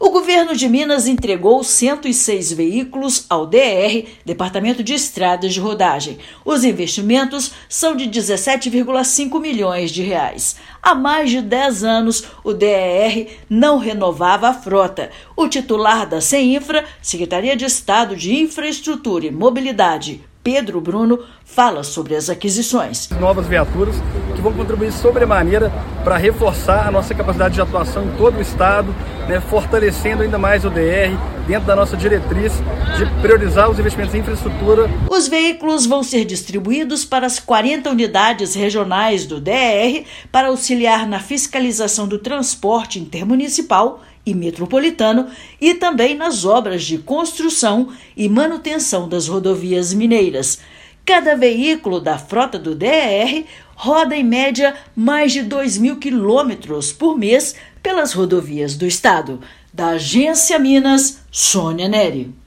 Departamento não renovava frota há mais de dez anos; veículos vão auxiliar na fiscalização das rodovias, transporte de passageiros e carga. Ouça matéria de rádio.